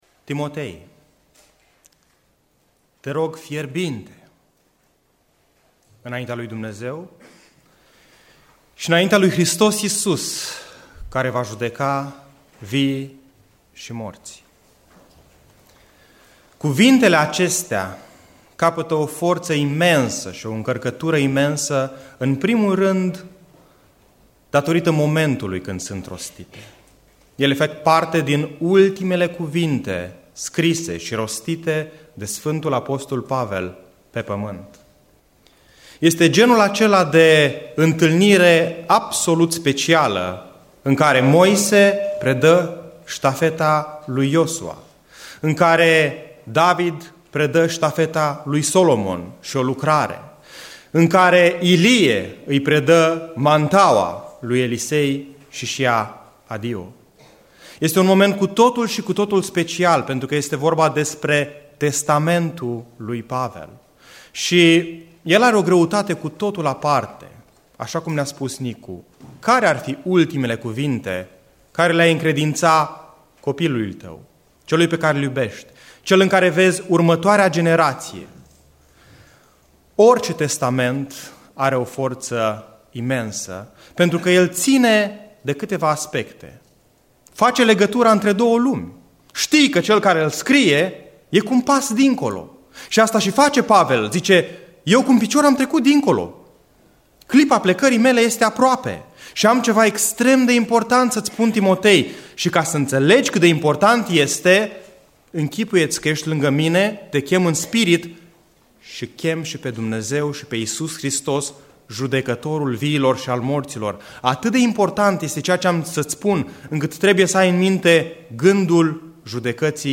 Predica Aplicatie 2 Timotei 4